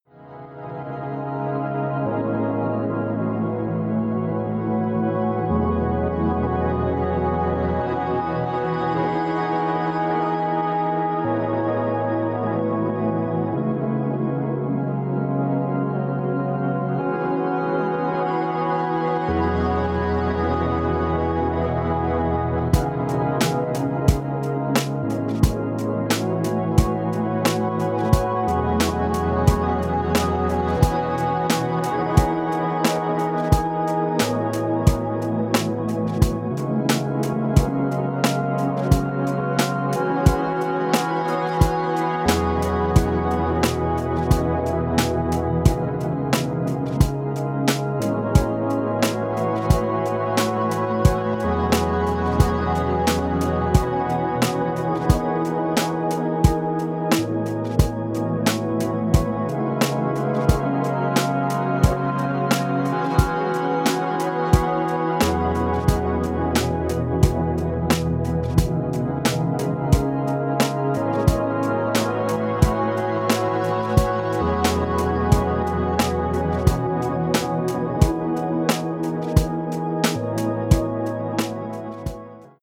ambient   down tempo   electronic   obscure dance